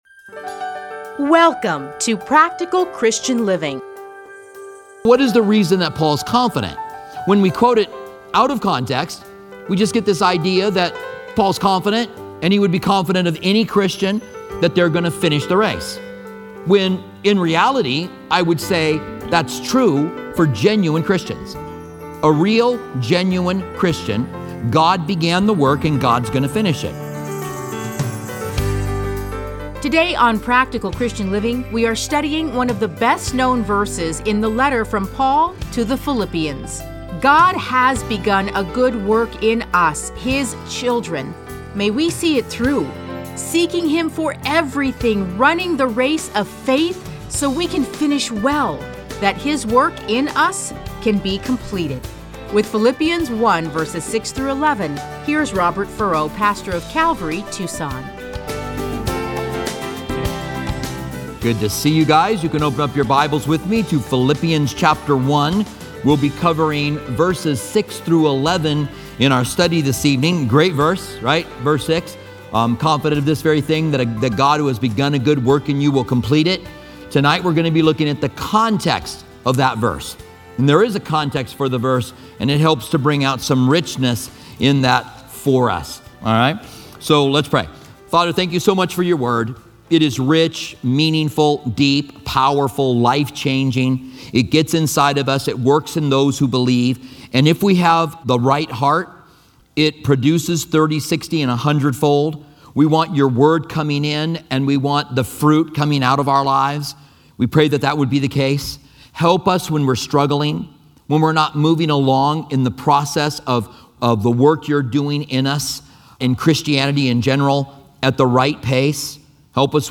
Listen to a teaching from A Study in Philippians 1:6-11.